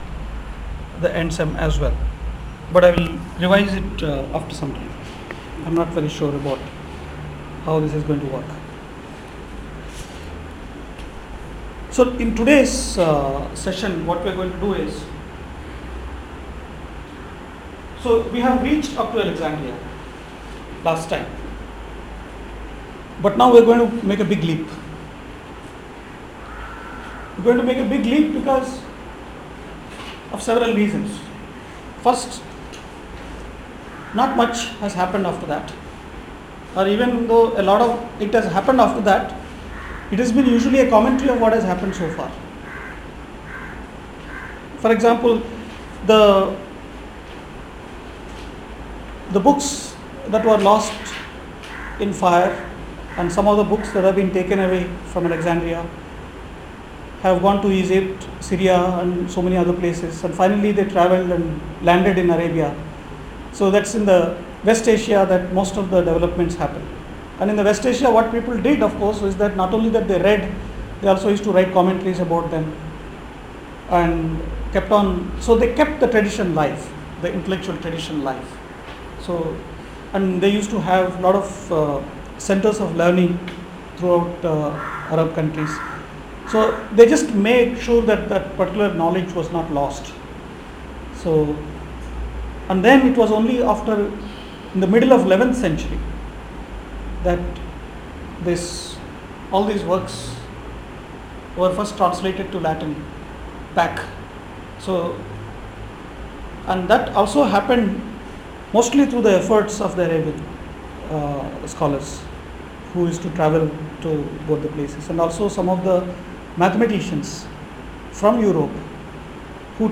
lecture 10